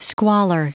Prononciation du mot squalor en anglais (fichier audio)
Prononciation du mot : squalor